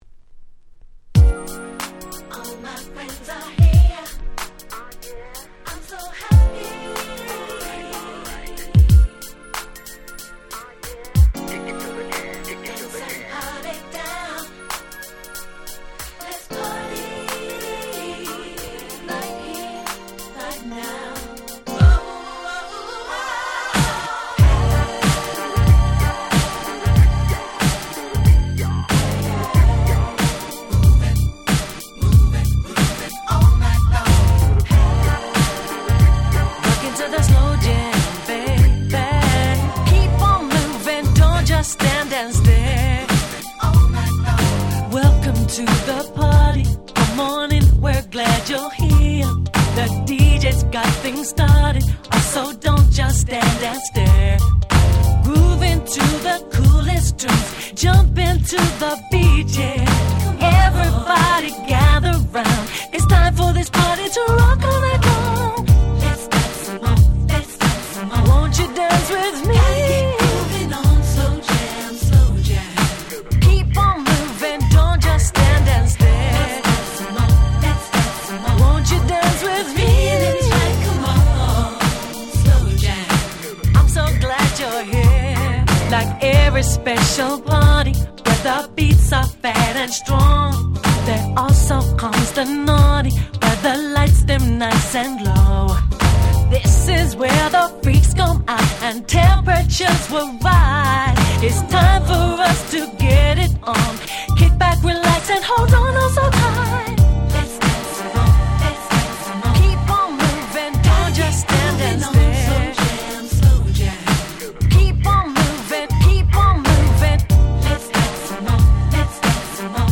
97' Super Nice UK R&B !!